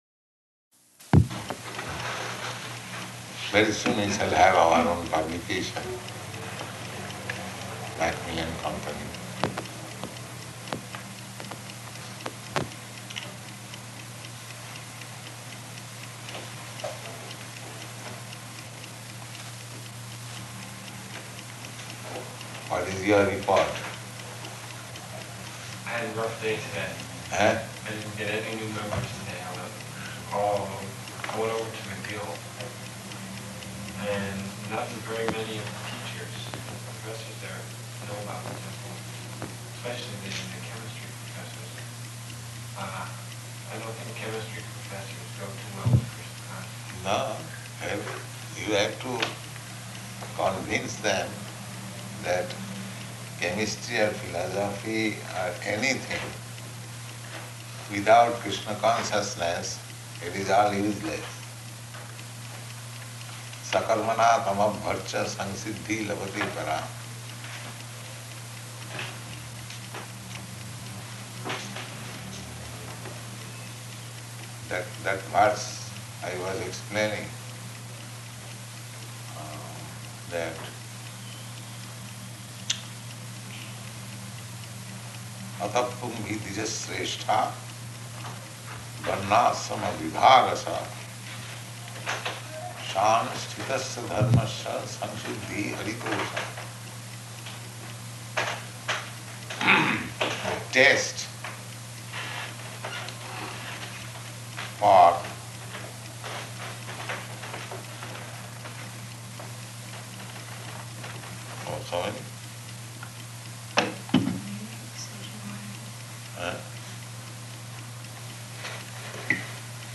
Room Conversation
Room Conversation --:-- --:-- Type: Conversation Dated: July 16th 1968 Location: Montreal Audio file: 680716R1-MONTREAL.mp3 Prabhupāda: Very soon we shall have our own publication, Macmillan Company.